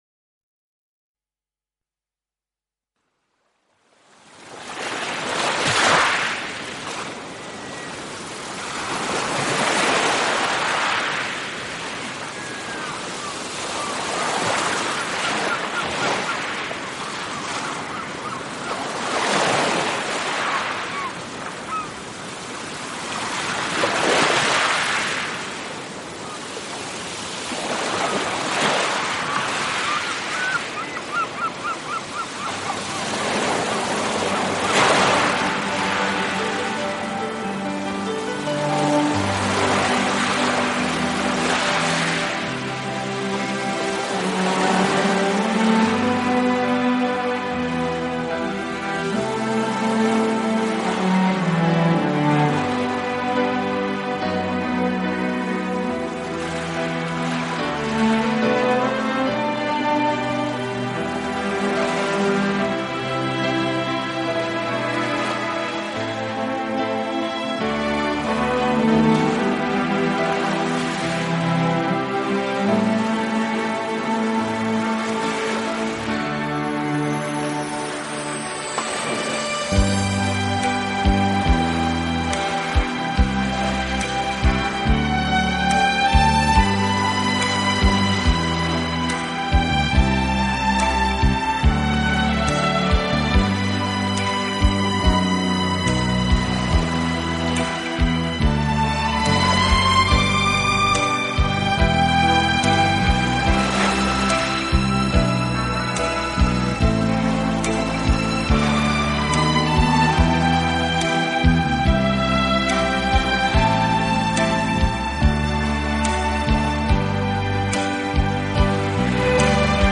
自然聲響與音樂的完美對話
海浪、流水、鳥鳴，風吹過樹葉，雨打在屋頂，
大自然的原始採樣加上改編的著名樂曲合成了天籟之音。